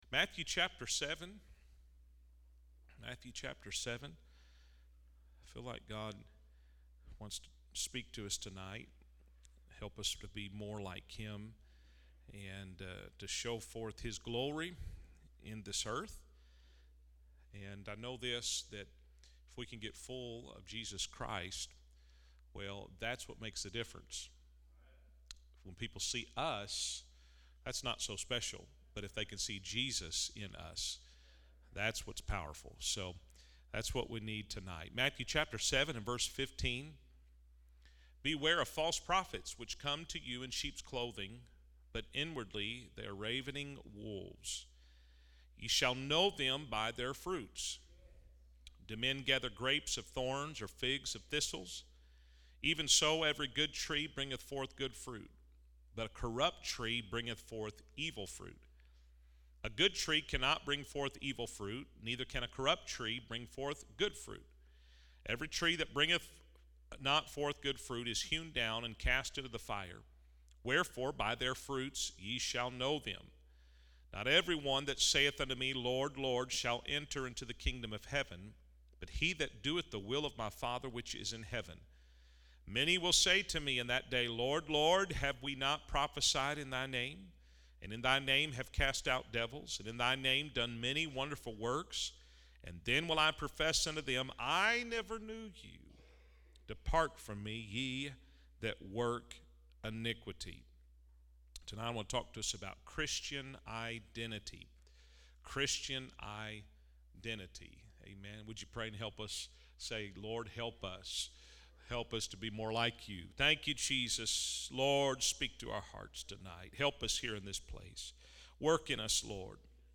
Through insightful analysis and practical wisdom, this sermon empowers believers to embrace their identity rooted in Christ, guiding them towards a deeper understanding of their purpose and calling.